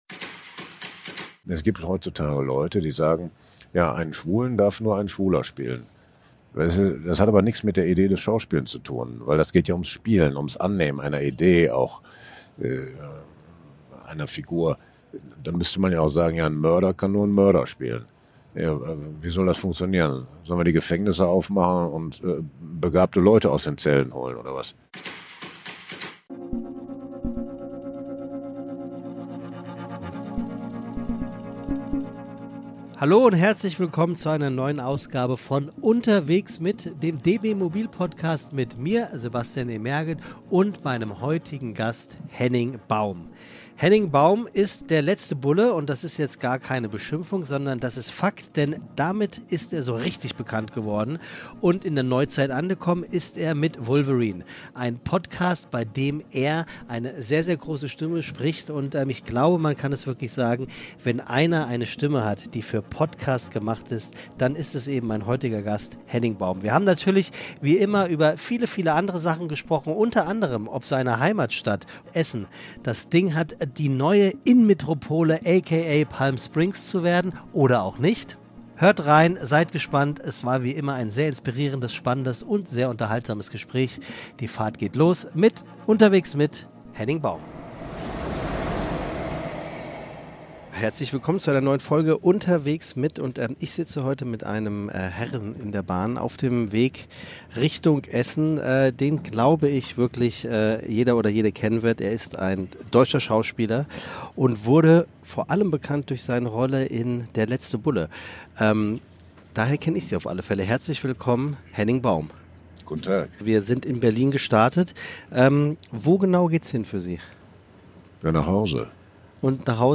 Mit dem ICE geht es von Berlin nach Essen, Baums Heimatstadt.